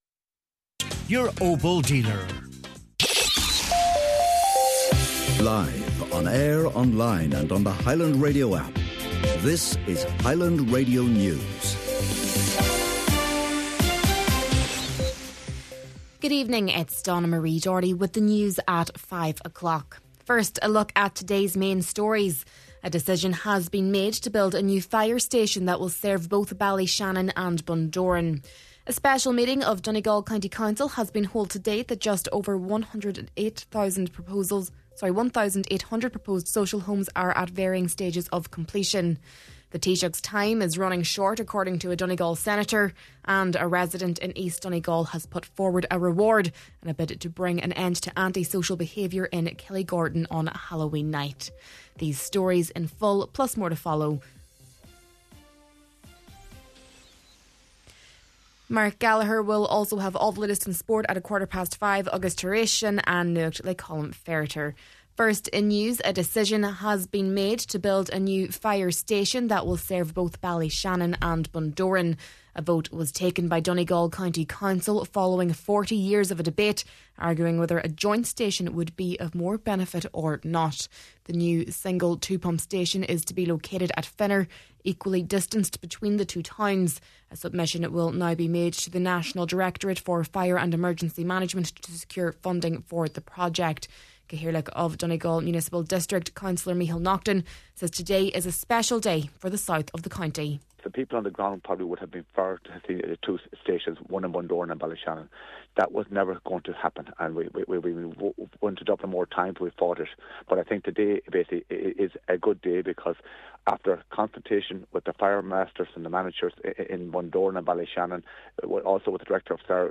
Main Evening News, Sport, An Nuacht and Obituaries – Friday, October 31st